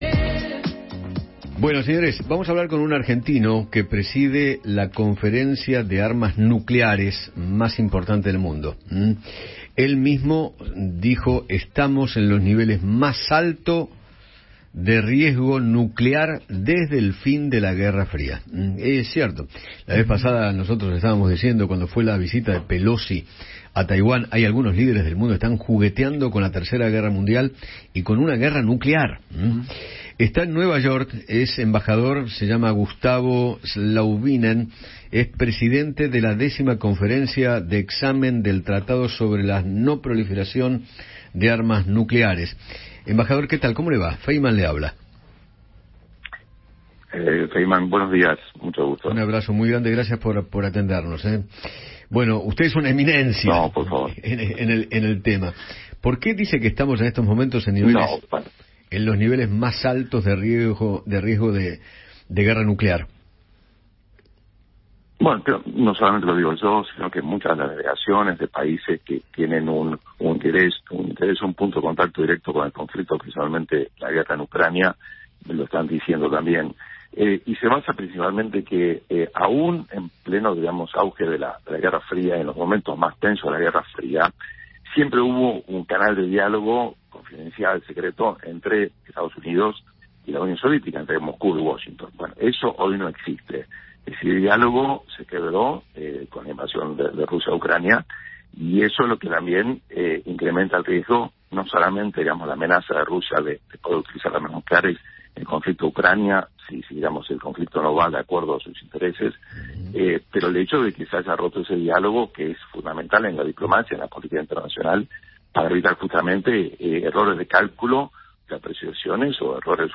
Gustavo Zlauvinen, titular de la Conferencia de Revisión del Tratado de No Proliferación de Armas Nucleares (TNP) de Naciones Unidas (ONU), dialogó con Eduardo Feinmann acerca del riesgo de una guerra nuclear y advirtió sobre el potencial de algunos países.